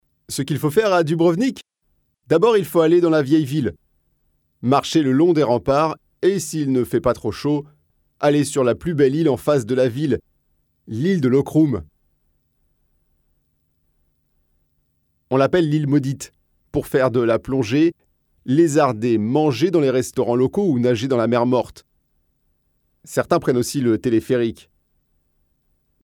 locutor frances, french voice over